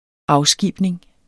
Udtale [ -ˌsgiˀbneŋ ]